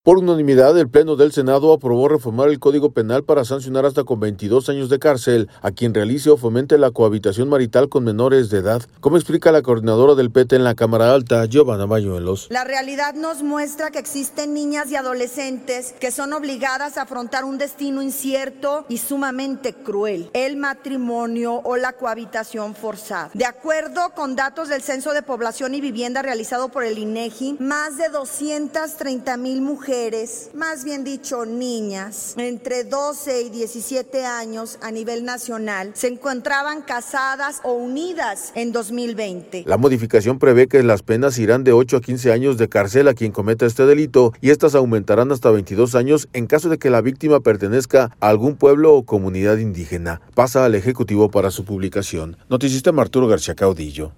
Por unanimidad, el Pleno del Senado aprobó reformar el Código Penal para sancionar hasta con 22 años de cárcel a quien realice o fomente la cohabitación marital con menores de edad, como explica la coordinadora del PT en la Cámara Alta, Geovanna Bañuelos.